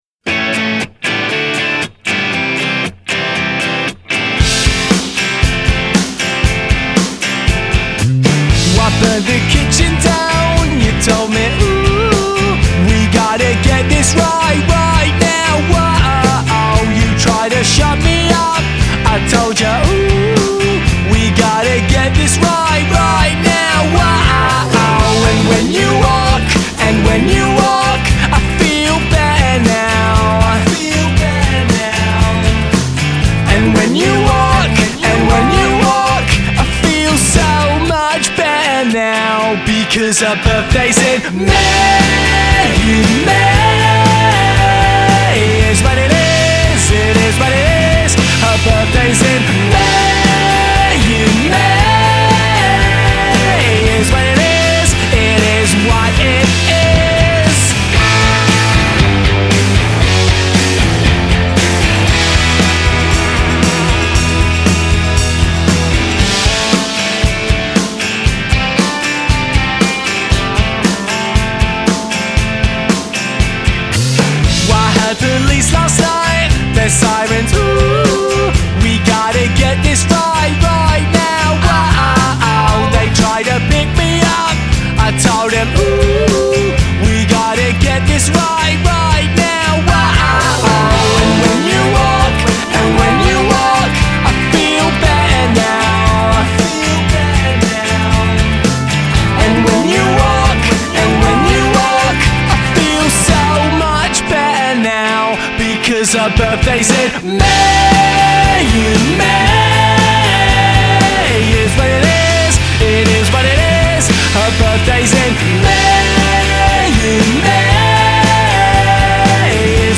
Their style is fun, simple and straight forward.
I like Lad rock.